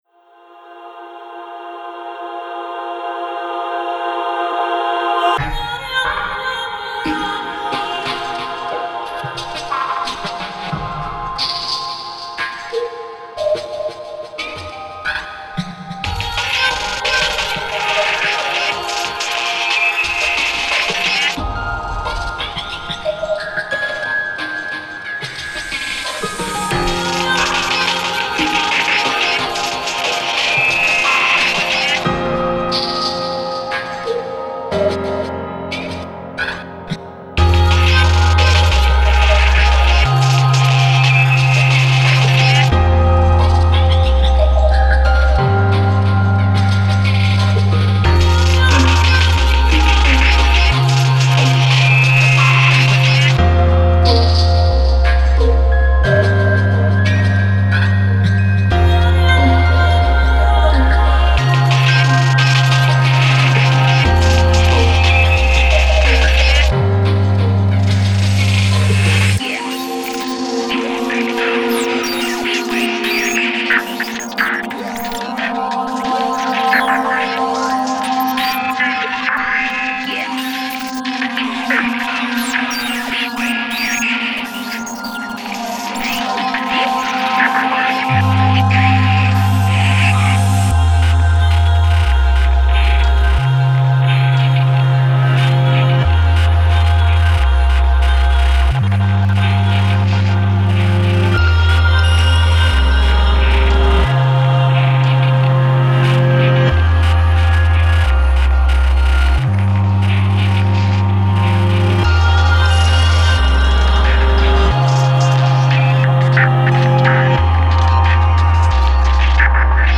幻想的なシンセサウンドと、神秘的なクワイア、デジタリックなリズムトラックとハイトーンのピアノサウンドが特徴の楽曲です。